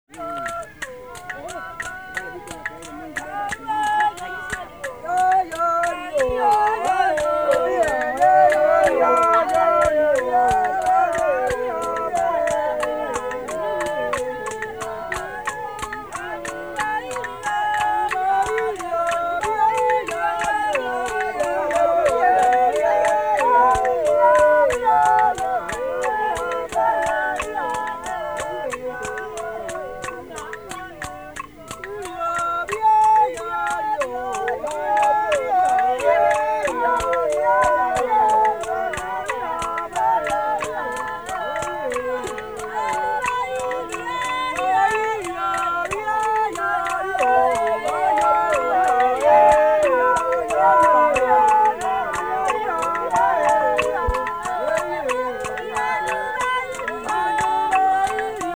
Rhythm stick
As regards sound, a clear and high pitch is preferred, supplementing the rest of the percussion, or to provide a rhythmical pulse for group singing. Generally the pattern is the same throughout the entire song and sometimes it is no more than a beat on each count.
This type of instrument is used in recordings of our sound archives made with the Congolese peoples mentioned hereafter where it appears with the following vernacular names: